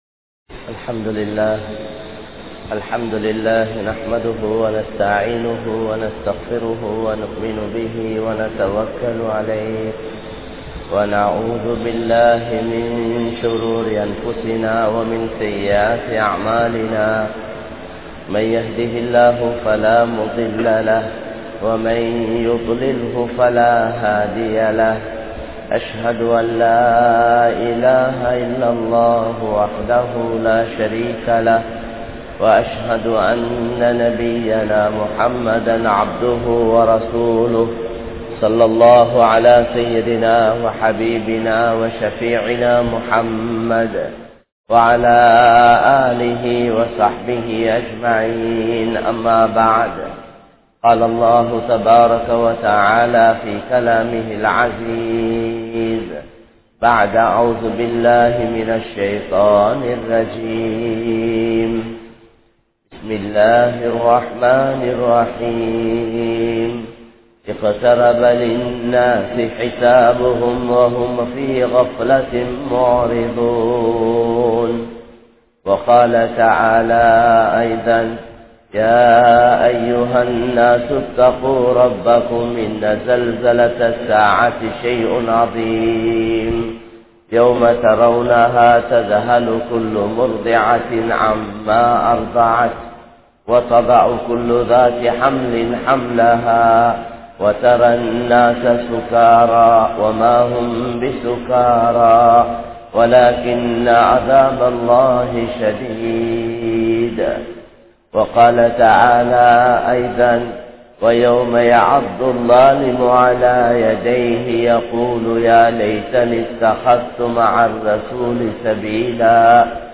Panathukkum Manithanukkum Indru Thrumanam(பணத்துக்கும் மனிதனுக்கும் இன்று திருமணம்) | Audio Bayans | All Ceylon Muslim Youth Community | Addalaichenai